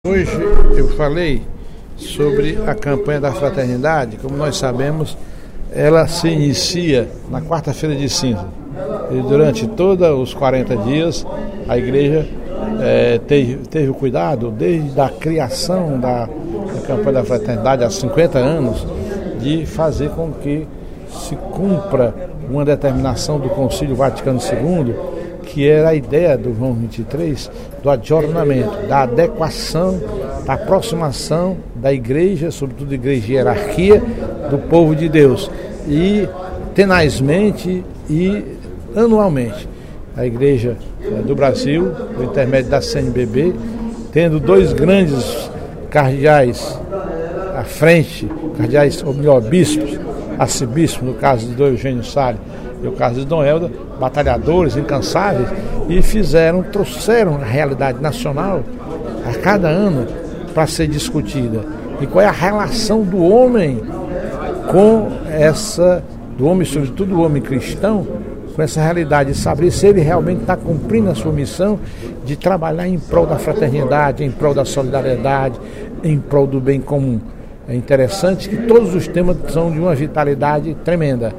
No primeiro expediente da sessão plenária desta quinta-feira (21/02), o deputado Professor Teodoro (PSD) ocupou a tribuna para destacar a Campanha da Fraternidade 2013, que este ano adotou o tema “Fraternidade e Juventude” e o lema: “Eis-me aqui, envia-me (Isaías 6,8)”.